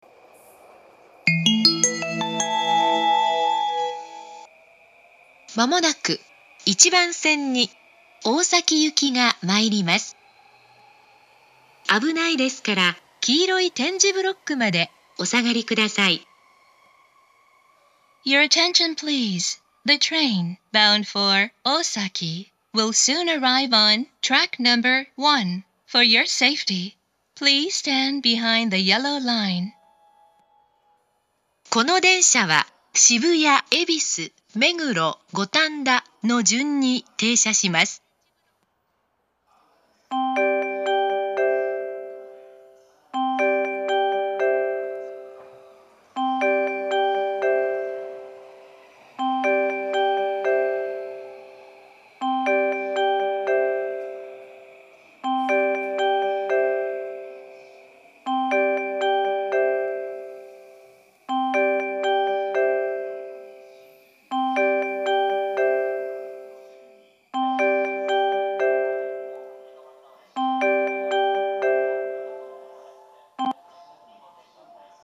１番線接近放送
終日、余韻までは鳴りやすく、混雑時は２コーラス目に入りやすいです。
ただ、入線メロディーがある関係で到着放送の鳴動はかなり遅いです。
harajuku1bansen-sekkin3.mp3